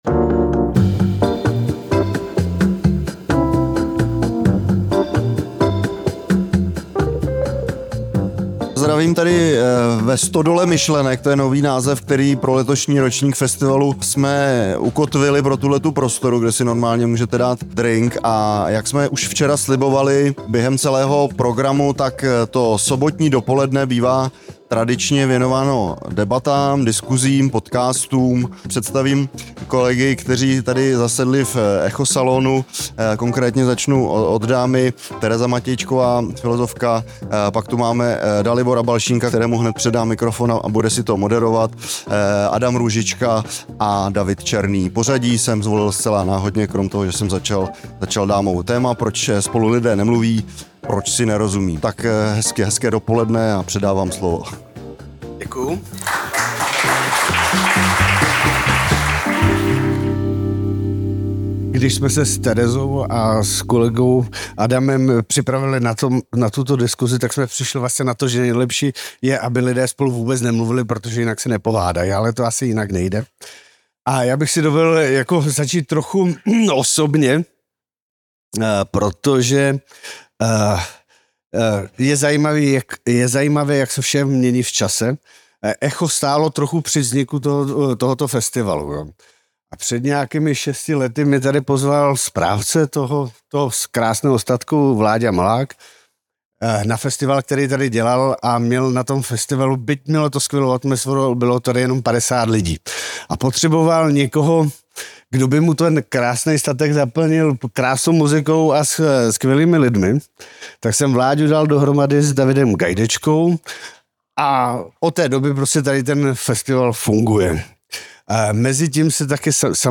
O tom se diskutovalo v sobotu 9. srpna ve Stodole myšlenek na festivalu Krásný ztráty ve středočeských Všeticích.
Slova se přeceňují. Salon Echa na festivalu Krásný ztráty